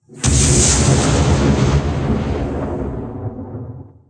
explode2.wav